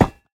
Minecraft Version Minecraft Version latest Latest Release | Latest Snapshot latest / assets / minecraft / sounds / block / netherite / step6.ogg Compare With Compare With Latest Release | Latest Snapshot
step6.ogg